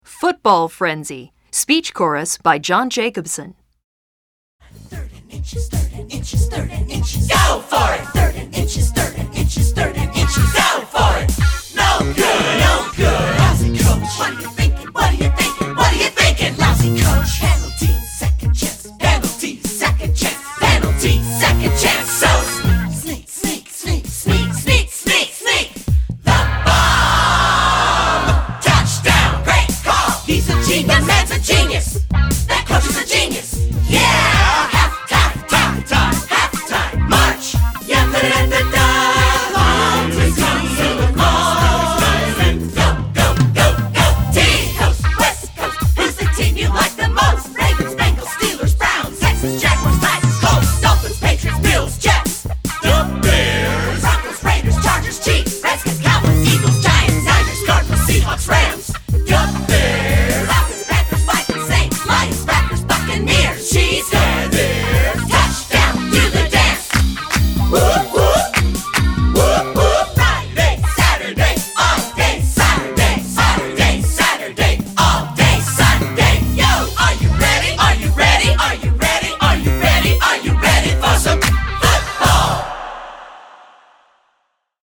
Voicing: Speech Cho